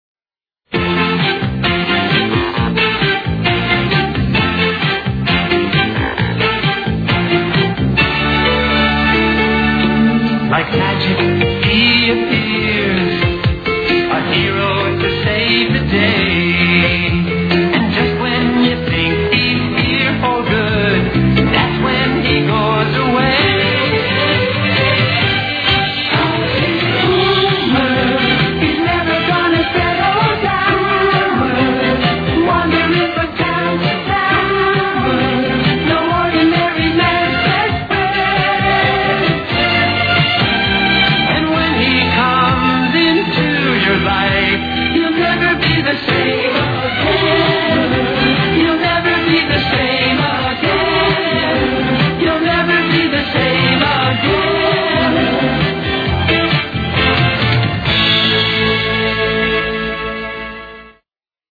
Weekly opening theme             1:01